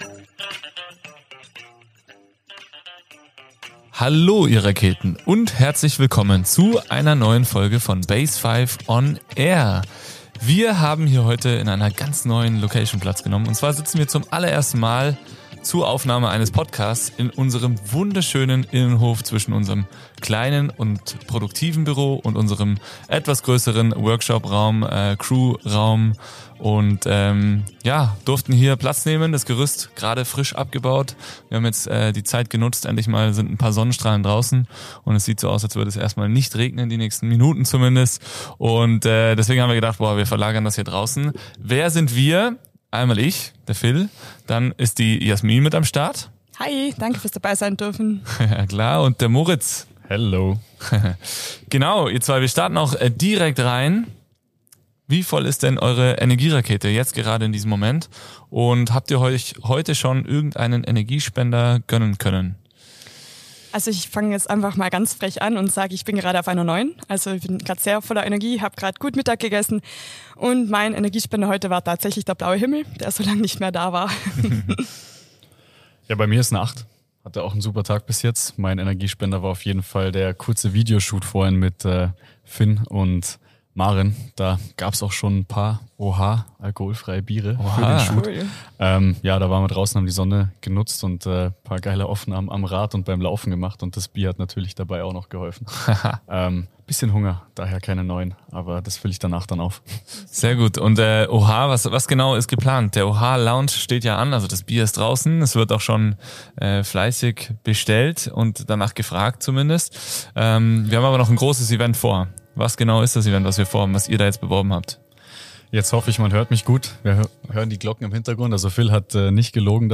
nehmen dich mit in den sonnigen Innenhof der BASEFIVE und sprechen über Salz, Schweiß, Recovery-Rituale und den Launch unseres alkoholfreien Biers „OHA“.